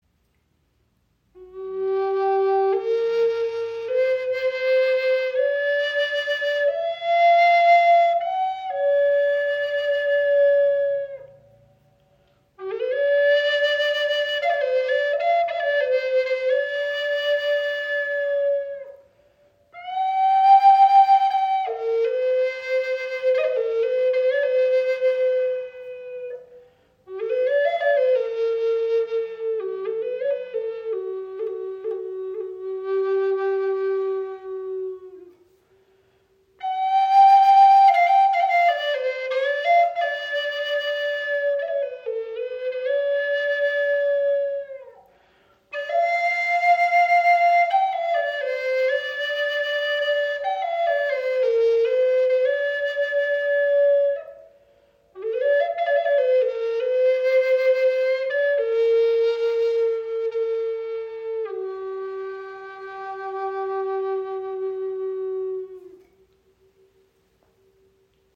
Diese Chakra-Flöte in G-Moll (432 Hz) aus edlem Walnussholz schenkt Dir eine klare, warme Stimme.